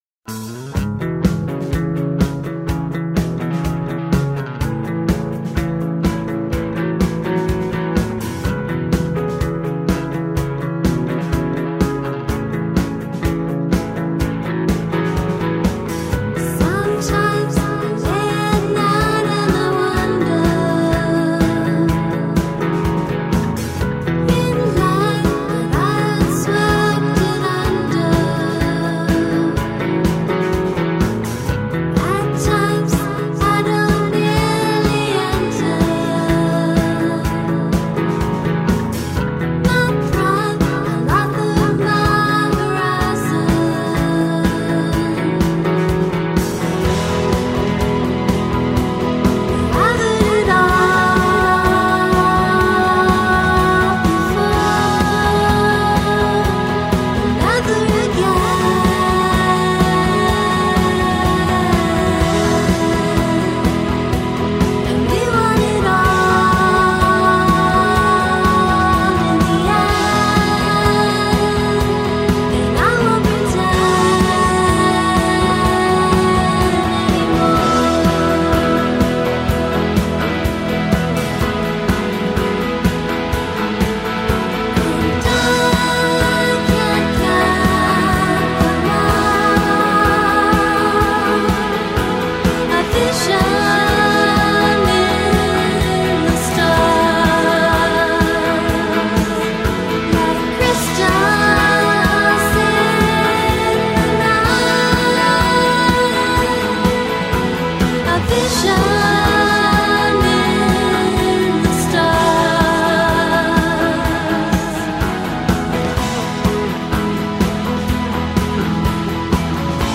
dream-pop three-piece